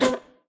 音效
Media:RA_Dragon_Chicken_atk_clean_002.wavMedia:RA_Dragon_Chicken_atk_clean_003.wavMedia:RA_Dragon_Chicken_atk_clean_004.wavMedia:RA_Dragon_Chicken_atk_clean_005.wav 攻击音效 atk 局内攻击音效